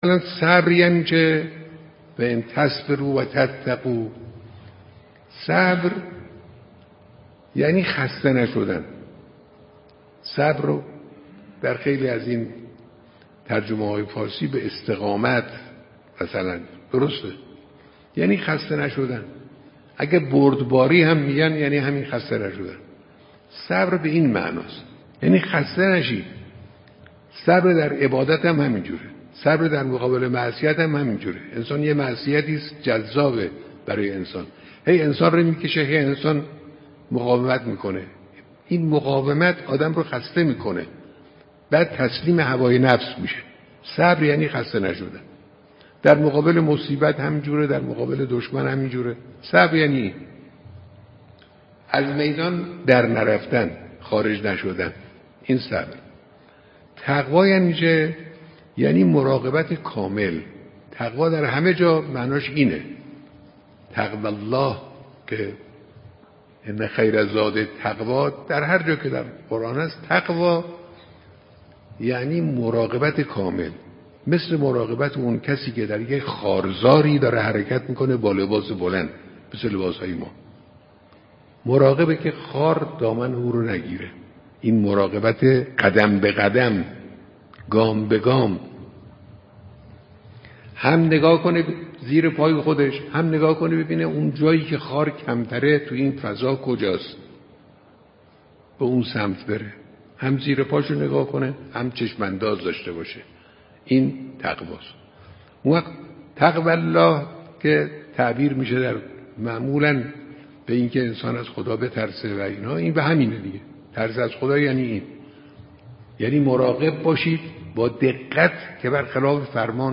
قطعه کوتاه صوتی از امام خامنه ای در خصوص صبر و تقوا